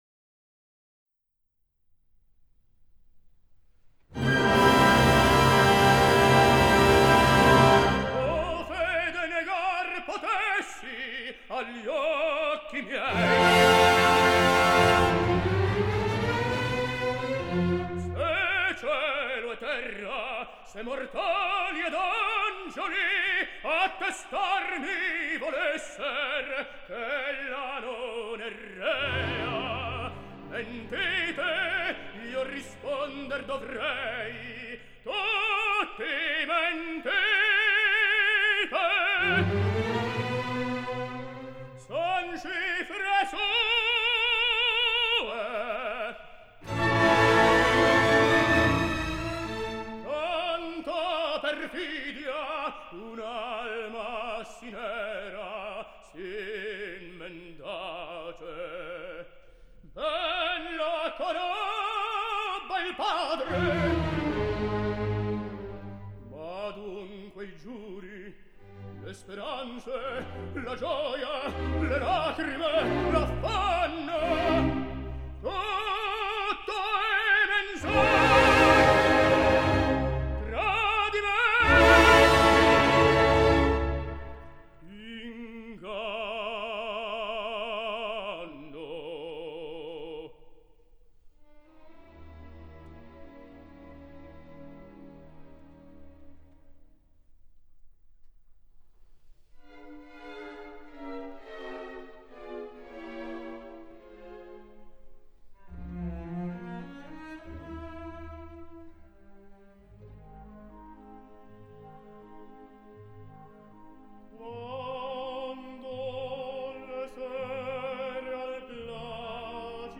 I el darrer tenor